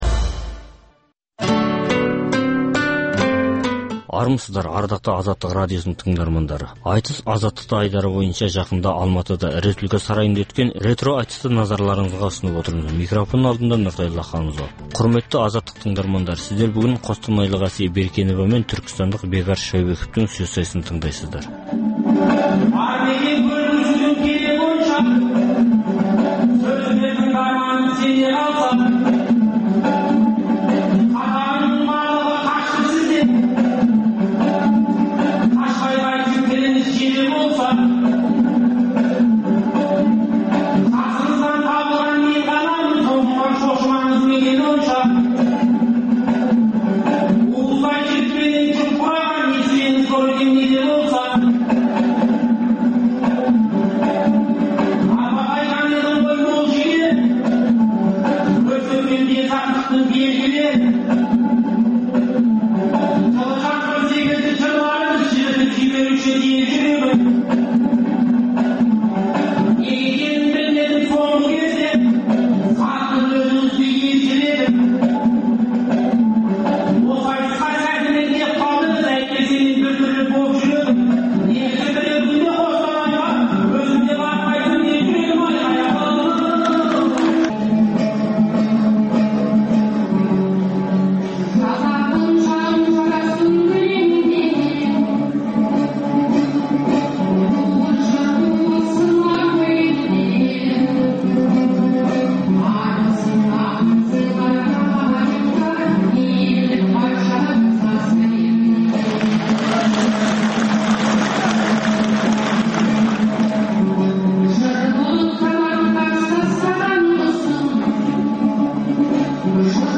Пікірталас клубы: Жастардың елдің бүгіні мен болашағы жайындағы ойлары мен көзқарас-пікірлері талқылаудың өзегі болады, сайлау мен сайлану, сөз бостандығы, ой еркіндігі, білім беру жүйесі төңірегіндегі жастардың ой-пікірлерін ортаға салу; Жастарды не толғандырады, тәртіп пен тәрбие туралы олар не ойлайды?